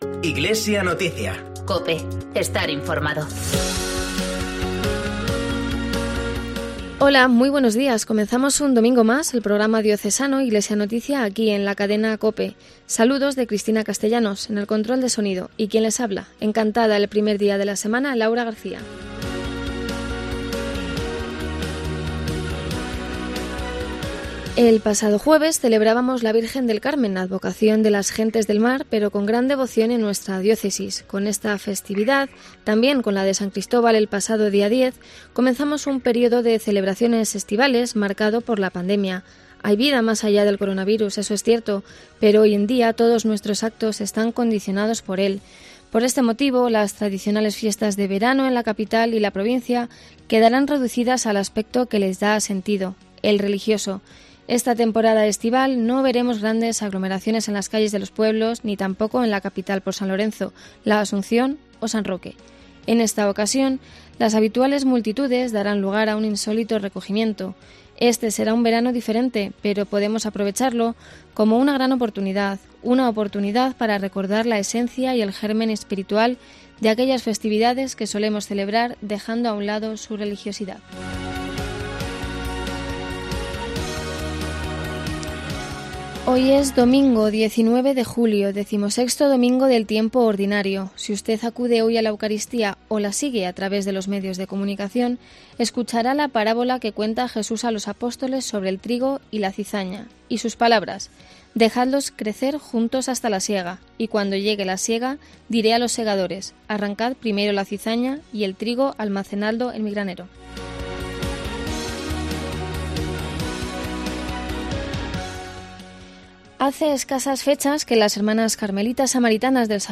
PROGRAMA RELIGIOSO